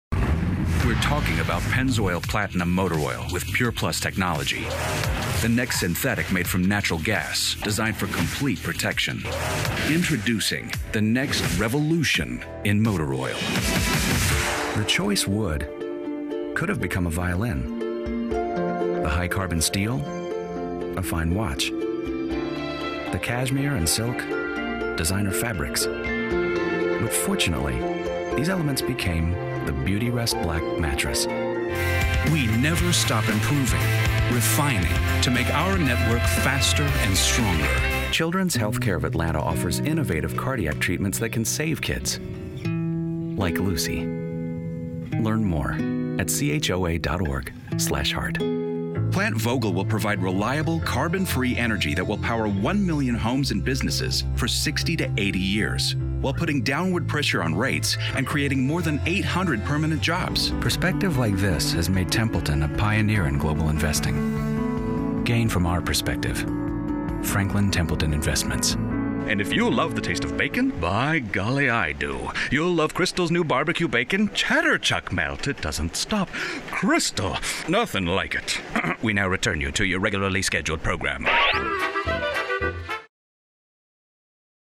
American, American Southern, British
I'm a professional voice actor recording from my Source-Connect Certified home studio in metro Atlanta:
⌲ Sennheiser MKH 416 Shotgun Mic
While clients like my friendly, calm, reassuring, relatable 'regular' voice (healthcare, investment, and mattress companies have shown me a lot of love), I have a strange range of tones, accents and dialects chambered and ready to roll.